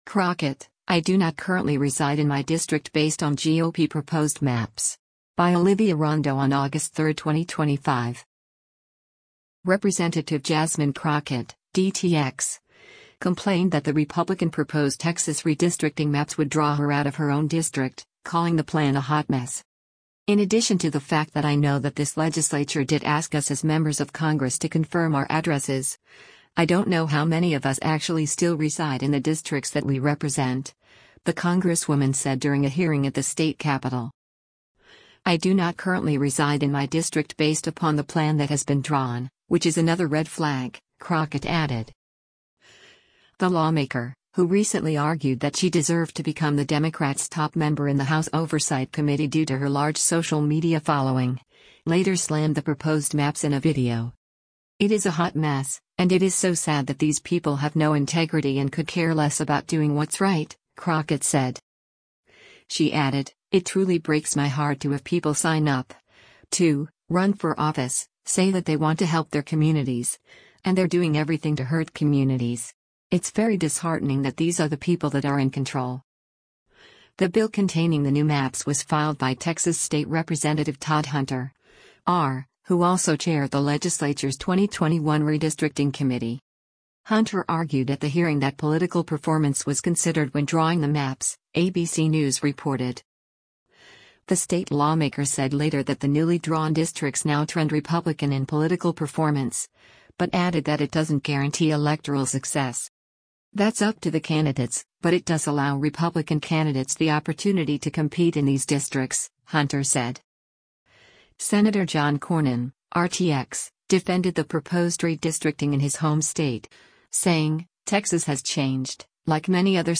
“In addition to the fact that I know that this legislature did ask us as members of Congress to confirm our addresses, I don’t know how many of us actually still reside in the districts that we represent,” the congresswoman said during a hearing at the state capitol: